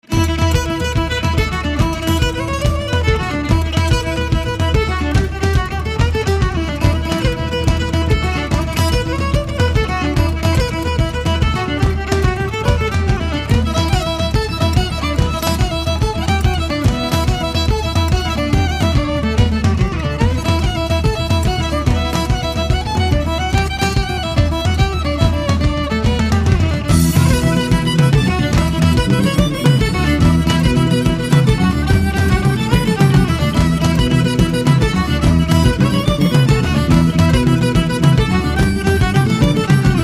A la différence de Peter Alexander Band, le répertoire de What est constitué uniquement de reprises, privilégiant les instruments acoustiques.
Note : le studio où a été enregistré cet album et les logements pour les musiciens sont troglodytes.